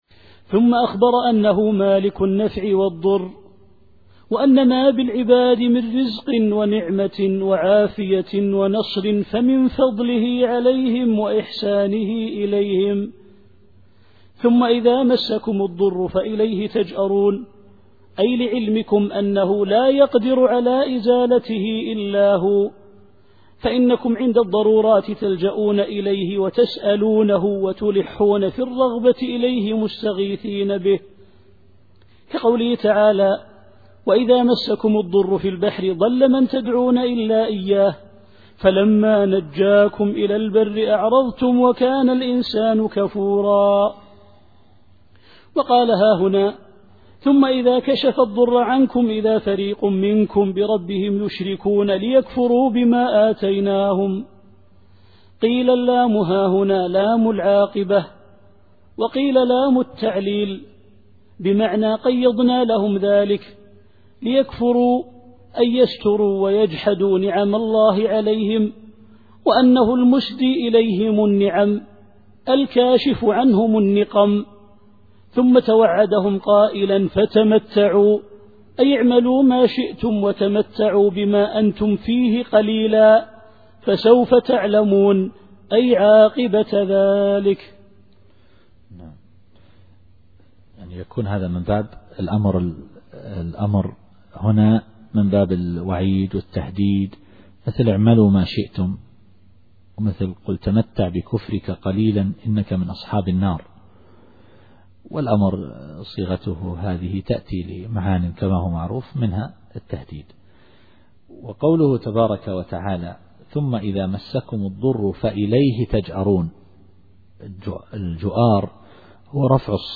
التفسير الصوتي [النحل / 55]